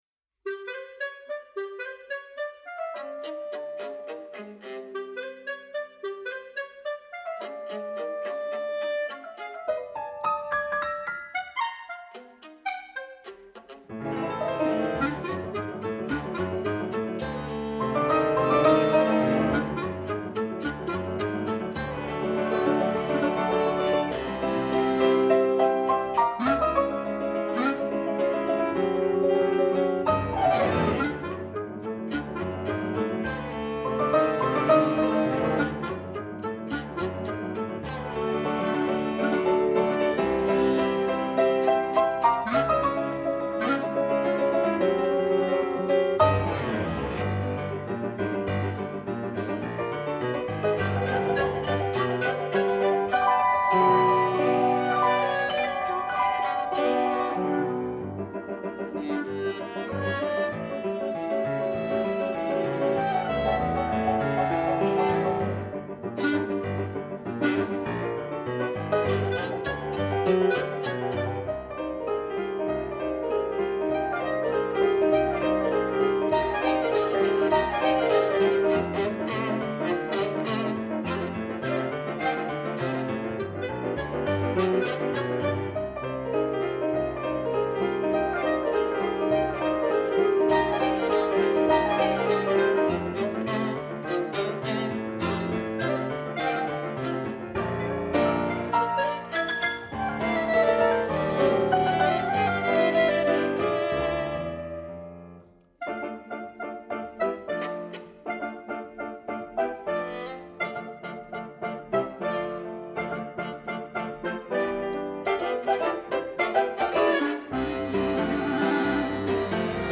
Clarinet
Viola
Piano